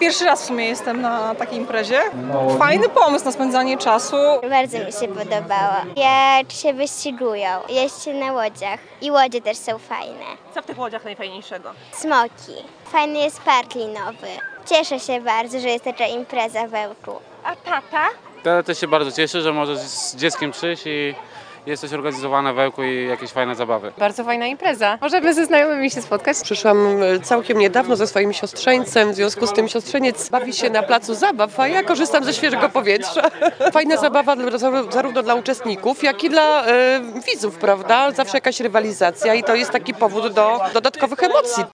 smocze-goscie.mp3